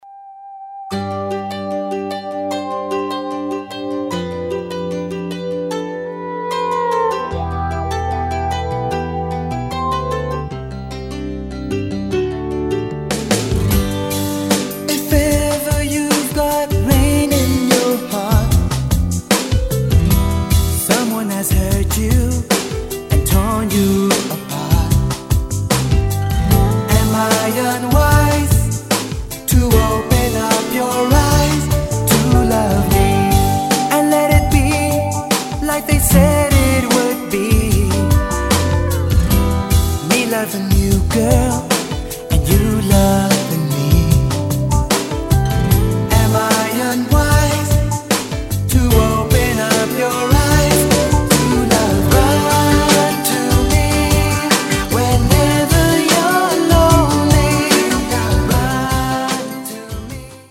(20 Latin 3-Step, Rumba & Slow Dance Favorites)<